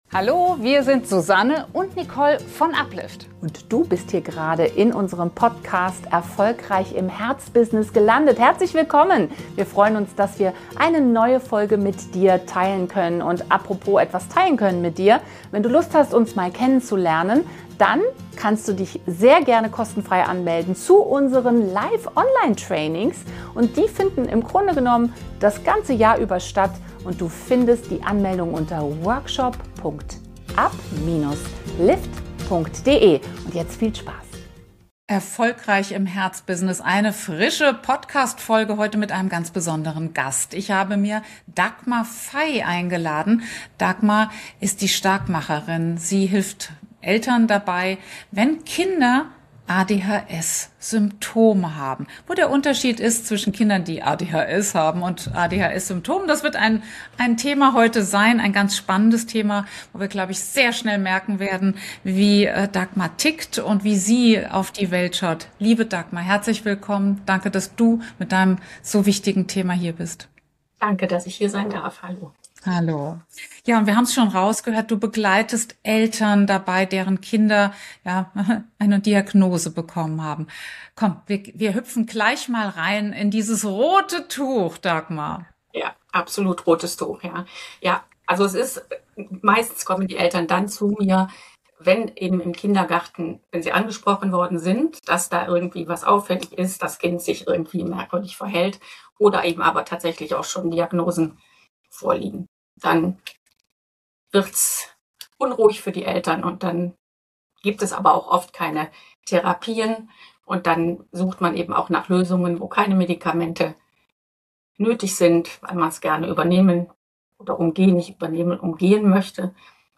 Ein Gespräch über Verantwortung, Vertrauen und die Chance, Kindern – und sich selbst – einen neuen Weg zu eröffnen.